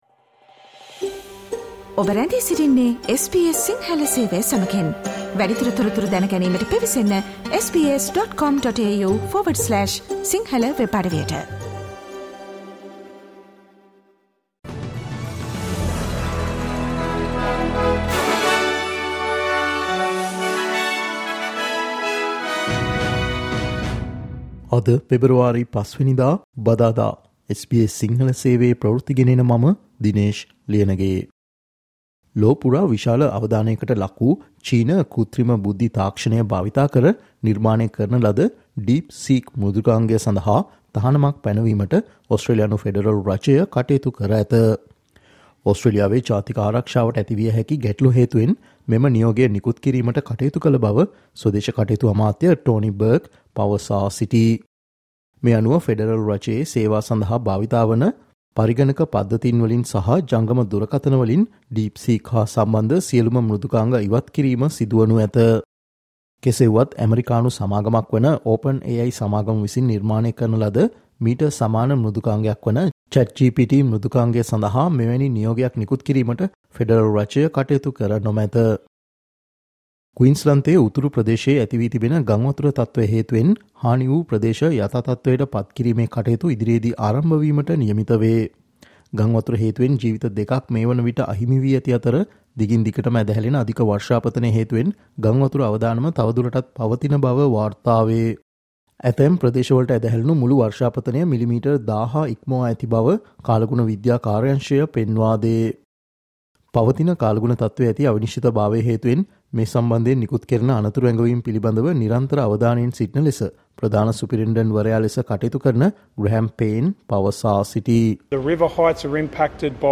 SBS Sinhala Newsflash 05 Feb: Australian government has imposed a ban on all DeepSeek products